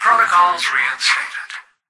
"Protocols reinstated" excerpt of the reversed speech found in the Halo 3 Terminals.